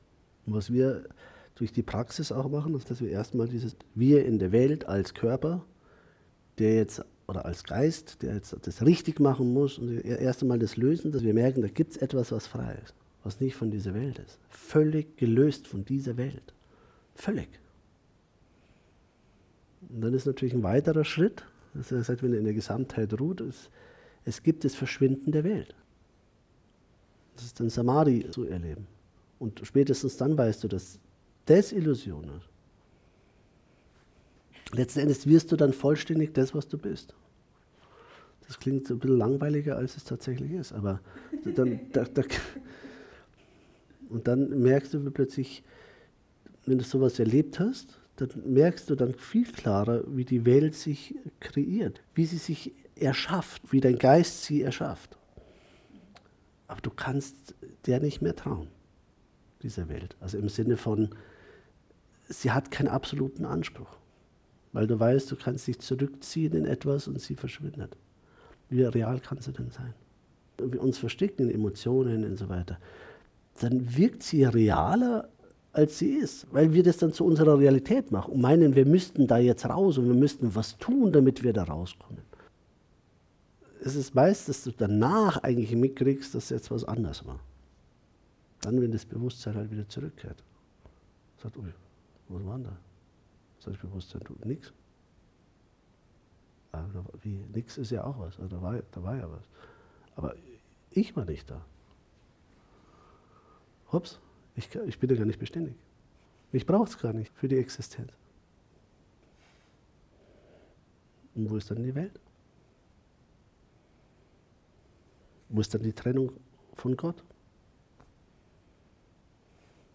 Satsangs und Meditation Live-Aufnahmen